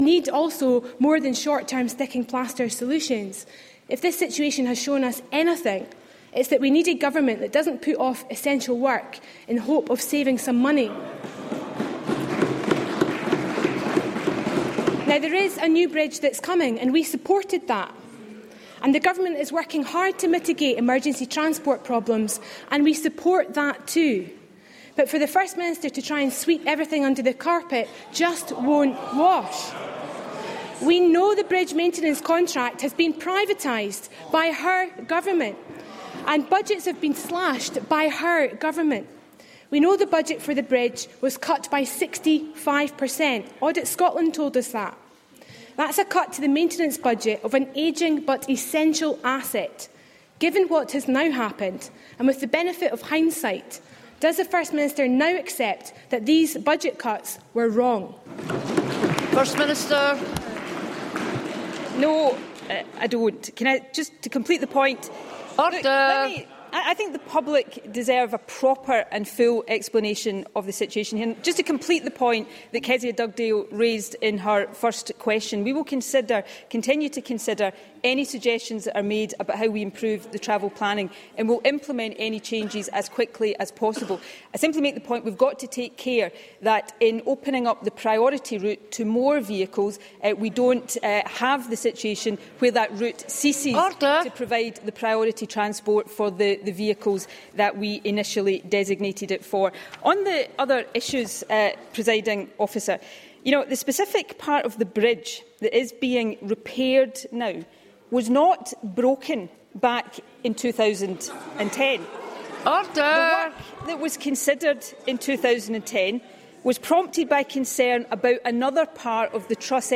Scottish Labour leader Kezia Dugdale and First Minister Nicola Sturgeon clash at First Ministers Questions over the closure of the Forth Road Bridge due to a steel defect